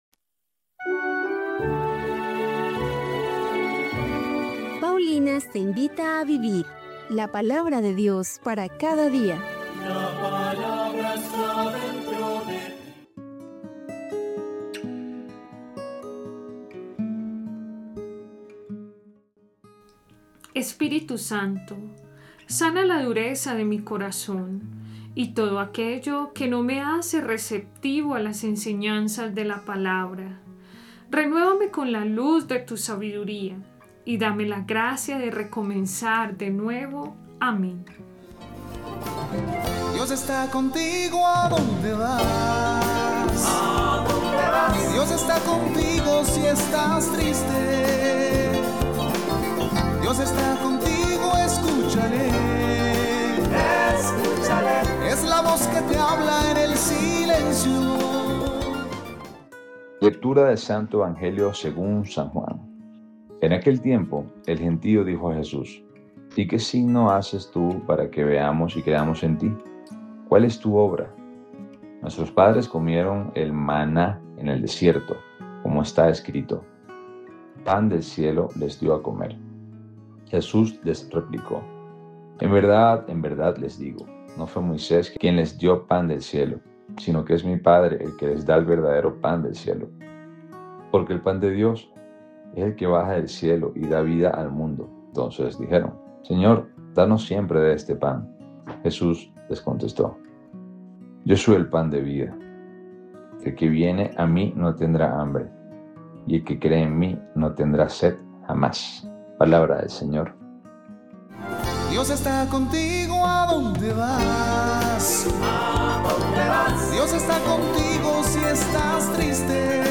Liturgia diaria